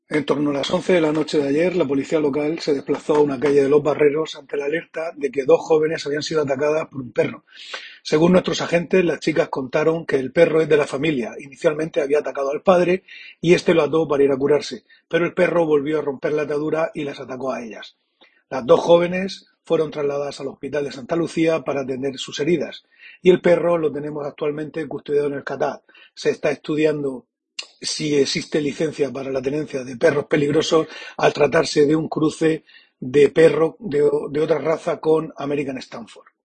Concejal de Sanidad de Cartagena sobre ataque de perro a dos jóvenes en Los Barreros